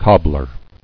[cob·bler]